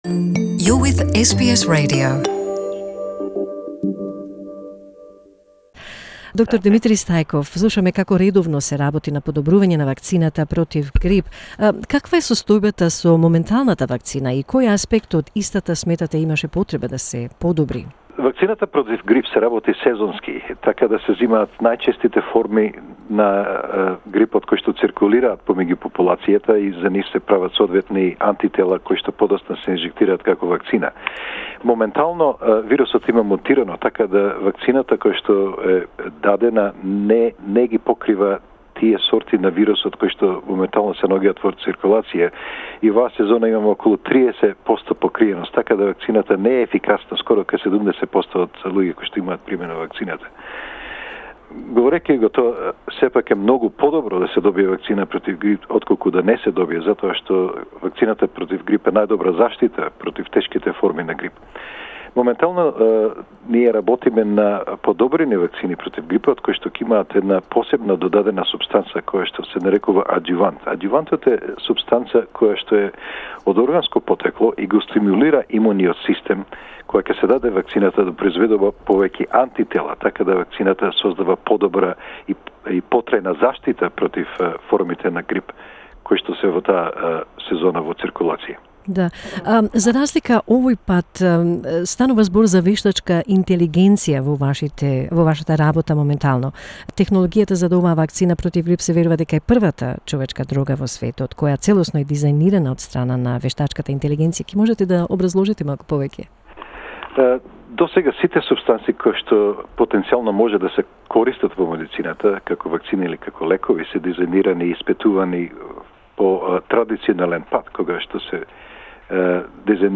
In an interview for SBS Macedonian